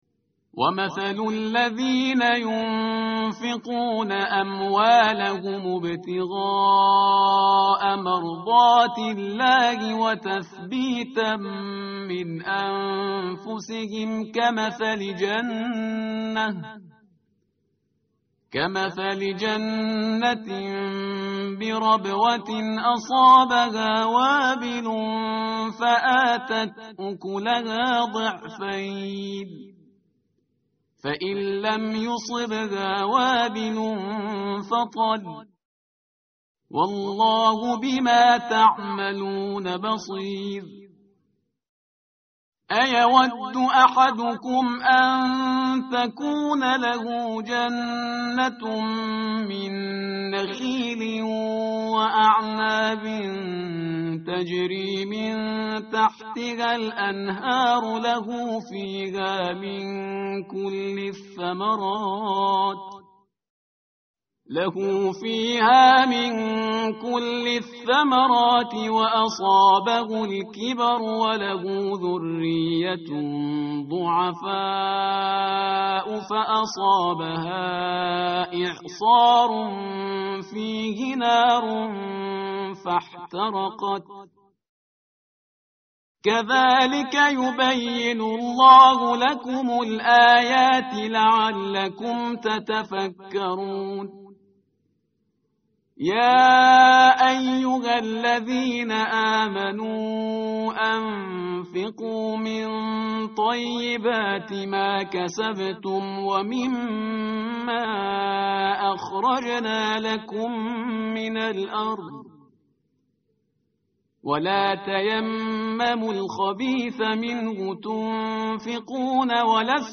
متن قرآن همراه باتلاوت قرآن و ترجمه
tartil_parhizgar_page_045.mp3